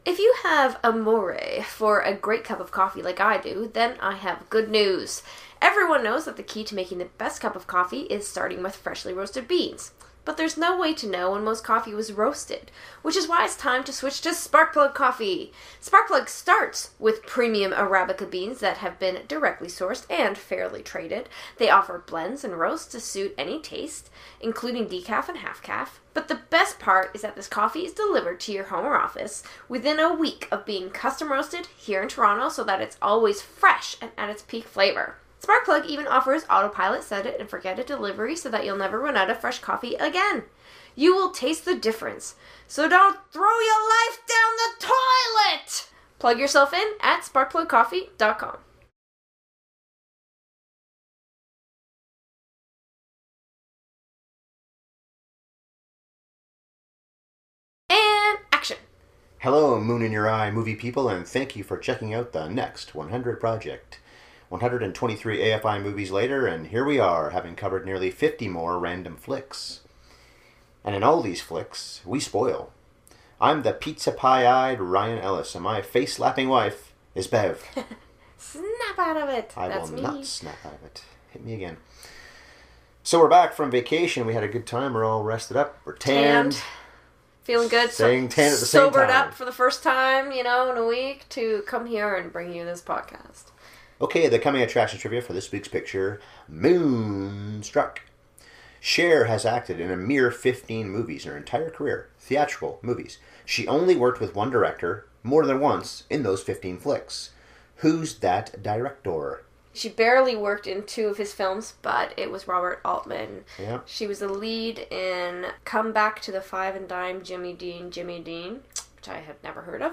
Cher and Olympia Dukakis are the main event here, but we pay tribute to the oddballity known as Nicolas Cage as well. We didn’t agree on this one, although our spirited debate does fit Moonstruck’s tone.